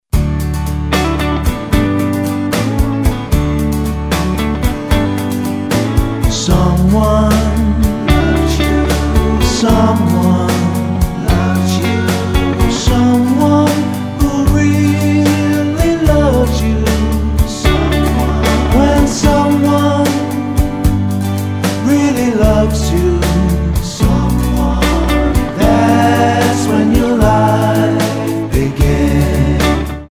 --> MP3 Demo abspielen...
Tonart:D-Eb Multifile (kein Sofortdownload.